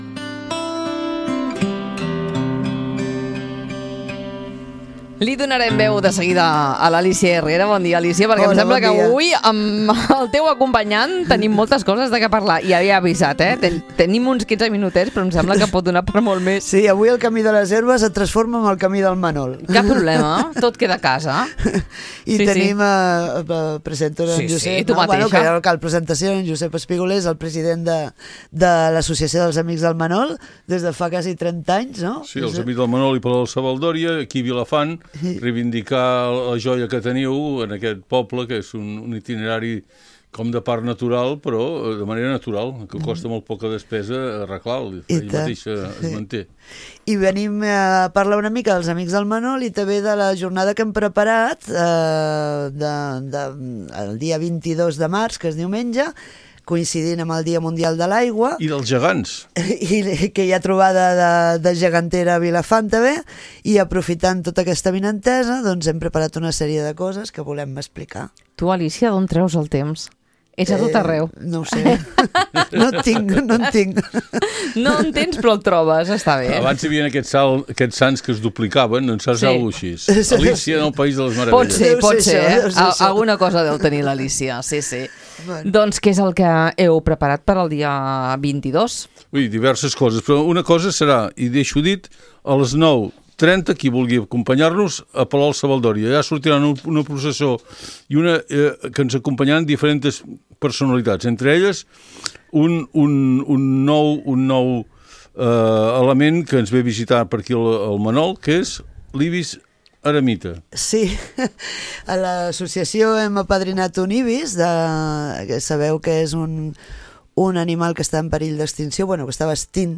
Durant la conversa hem parlat de les activitats previstes pel 22 de març, coincidint amb el Dia Mundial de l’Aigua i amb una nova trobada gegantera a Vilafant.